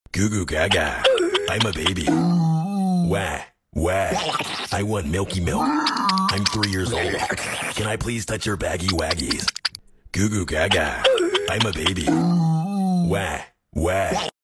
Goofy-ahh-noises.mp3